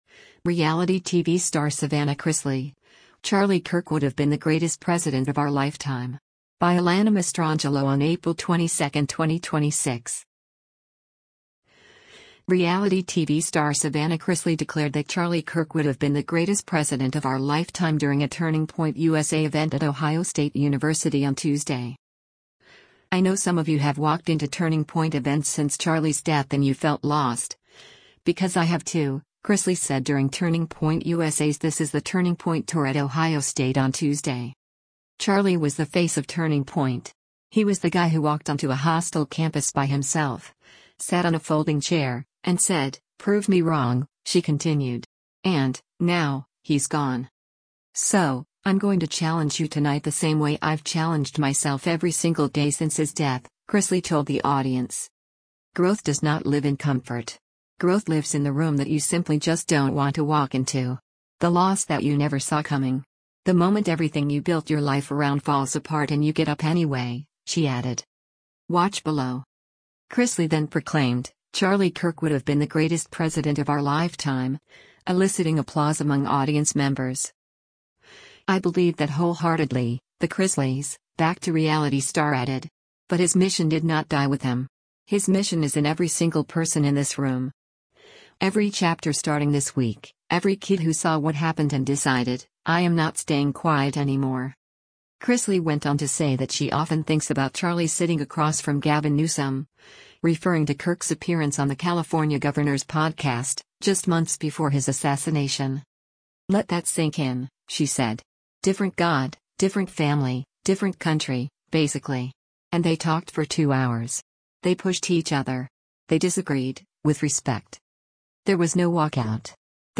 Reality TV star Savannah Chrisley declared that “Charlie Kirk would have been the greatest president of our lifetime” during a Turning Point USA event at Ohio State University on Tuesday.
Chrisley then proclaimed, “Charlie Kirk would have been the greatest president of our lifetime,” eliciting applause among audience members.